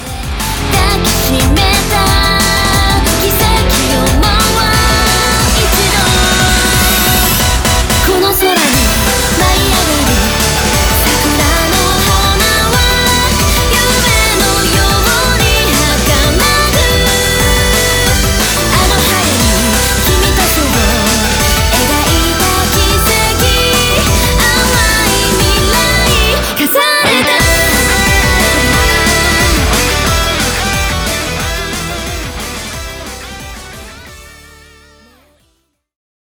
J-POP